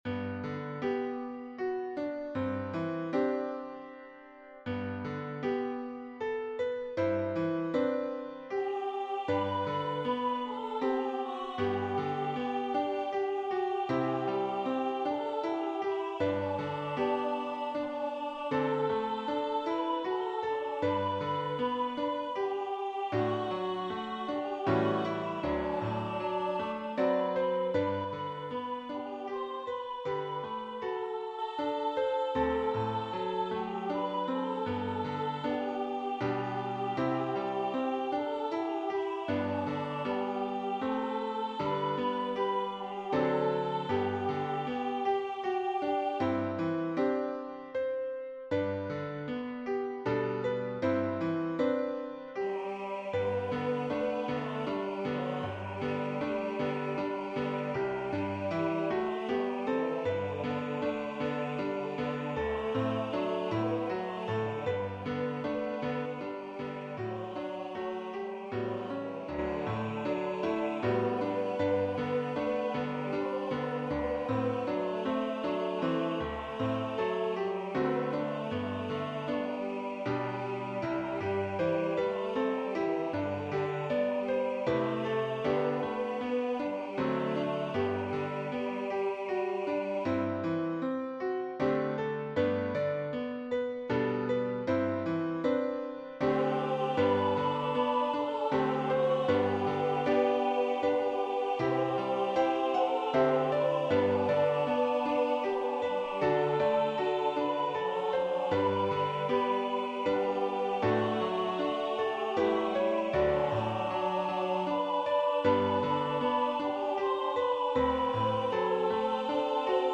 Voicing/Instrumentation: SATB We also have other 79 arrangements of " I Need Thee Every Hour ".